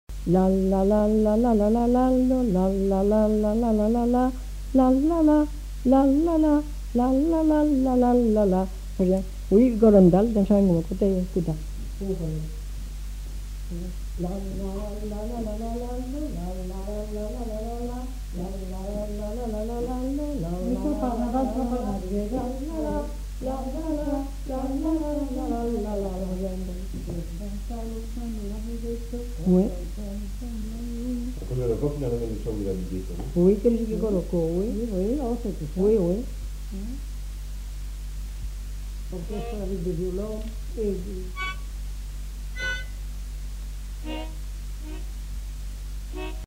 Aire culturelle : Haut-Agenais
Lieu : Cancon
Genre : chant
Effectif : 2
Type de voix : voix de femme
Production du son : fredonné
Danse : bigue-biguette